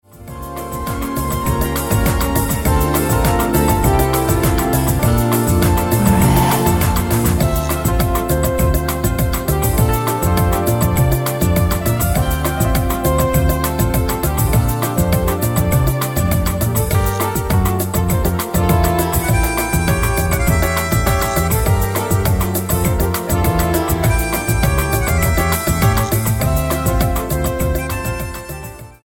STYLE: Latin